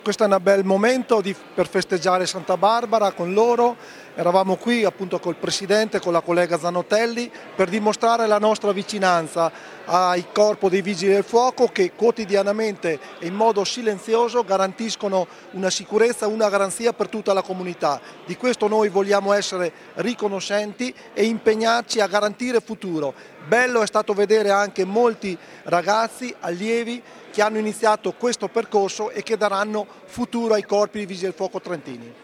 Int_Vicepresidente_Tonina_(1).mp3